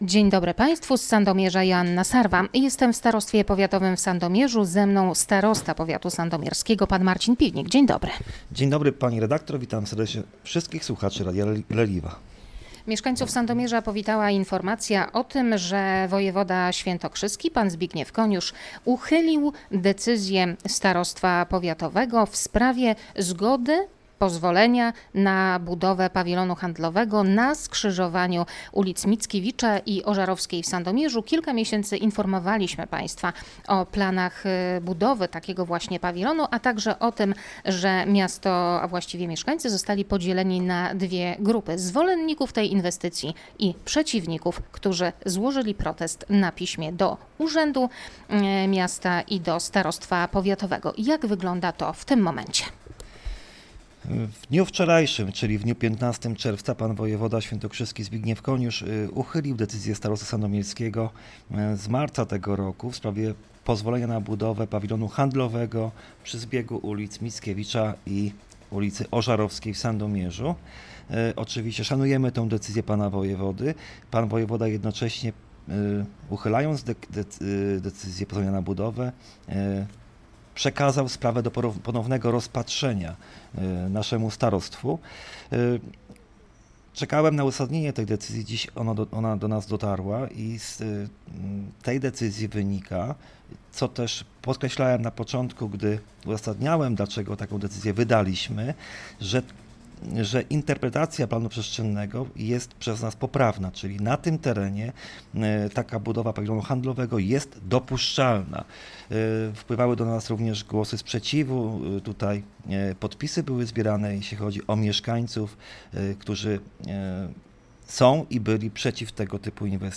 Wojewoda świętokrzyski Zbigniew Koniusz uchylił decyzję Starostwa Powiatowego w Sandomierzu w sprawie pozwolenia na budowę pawilonu handlowego u zbiegu ulic Mickiewicza i Ożarowskiej. – Chodzi o sprawy formalne – mówi starosta sandomierski Marcin Piwnik w rozmowie z Radiem Leliwa: